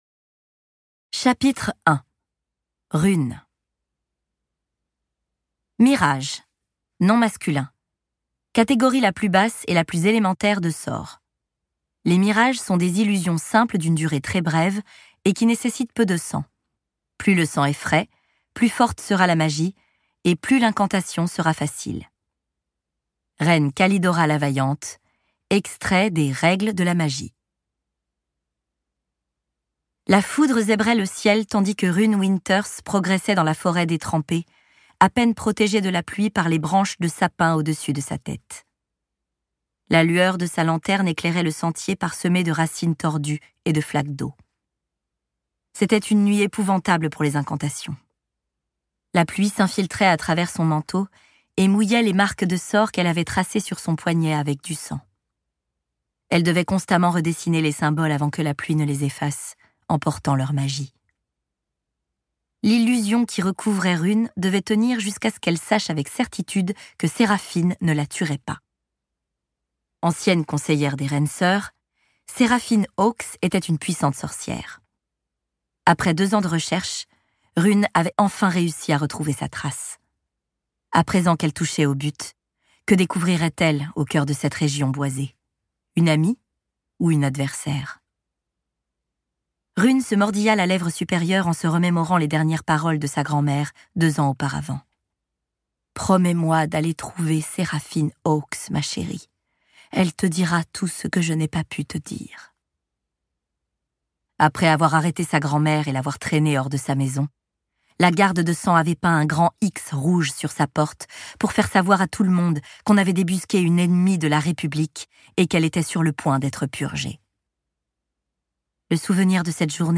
porté par la voix éraillée
le timbre profond